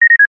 Звуки разговоров по рации
СБ-радио гудок, кнопка. Версия 4